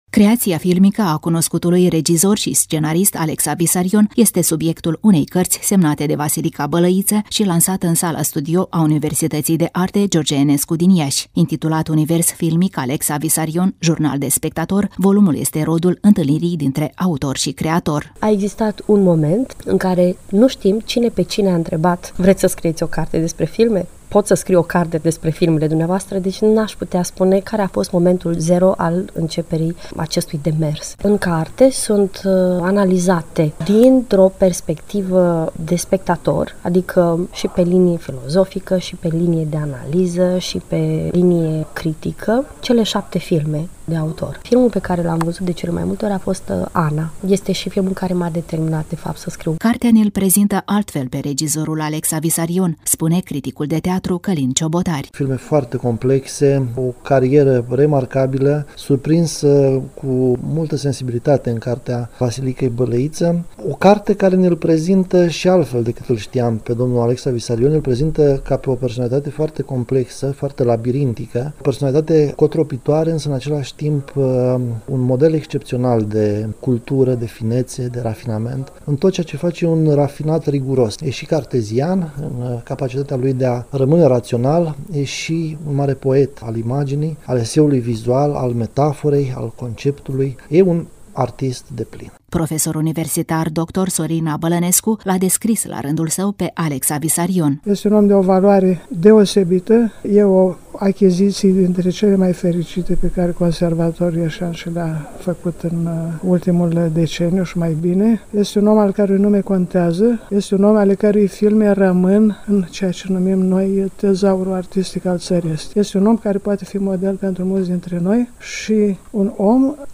„Univers filmic – Alexa Visarion (Jurnal de spectator)” – Reportaj cultural
În Sala Studio Teatru a Universității de Arte „G.Enescu” din Iași a avut loc lansarea cărții „Univers filmic – Alexa Visarion (Jurnal de spectator)”
Evenimentul s-a bucurat de participarea extraordinară a maestrului Alexa Visarion.